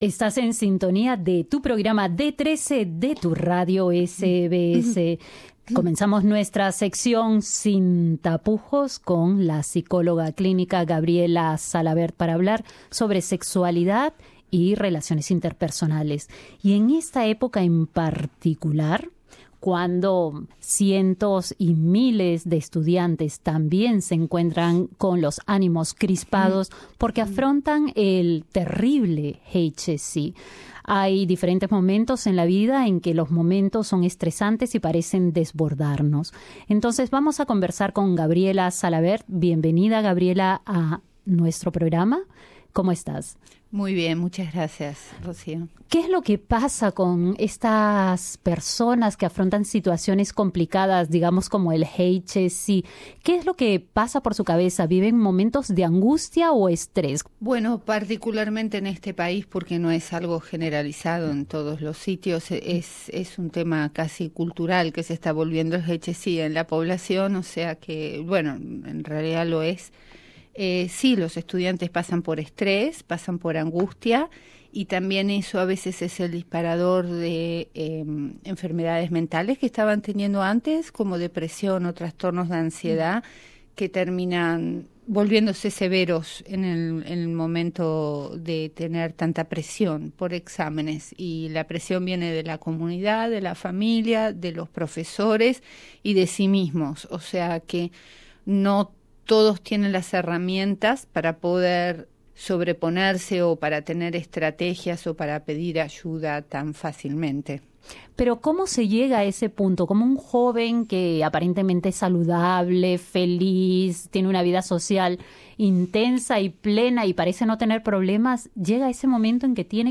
En SIN TAPUJOS, nuestra sección mensual sobre sexualidad y relaciones interpersonales dialogamos